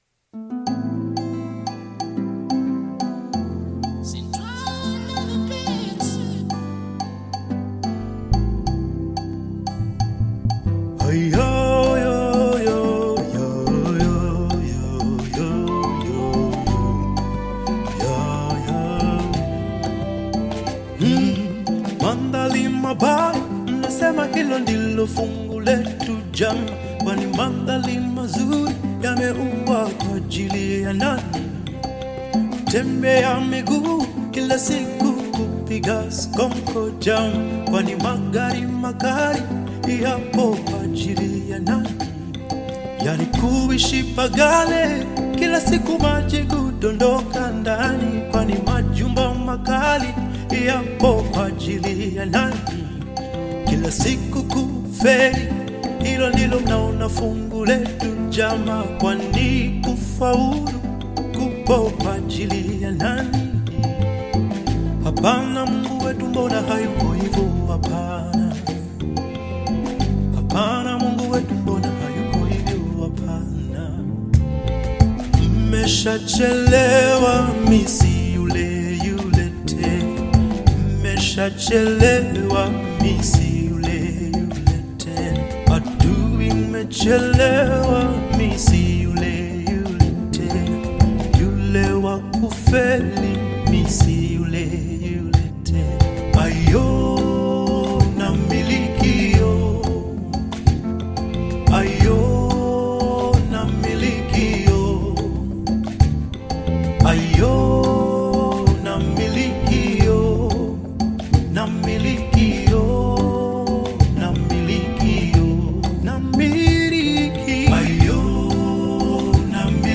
Gospel music track